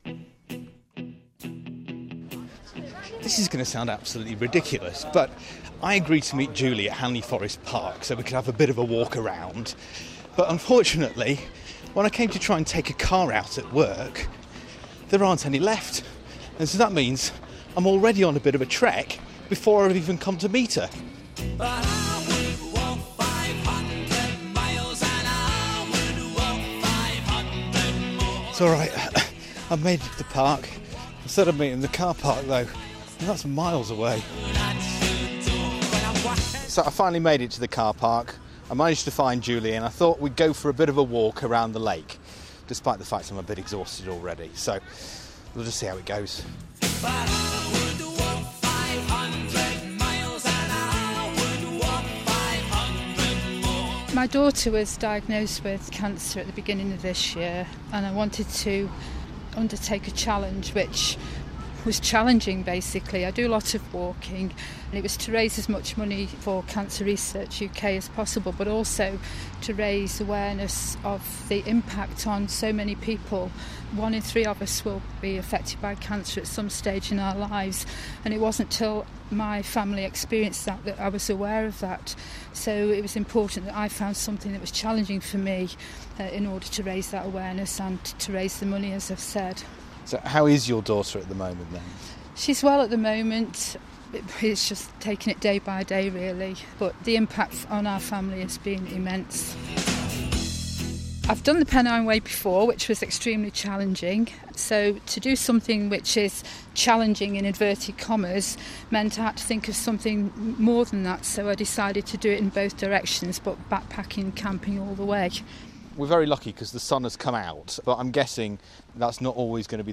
Here's my report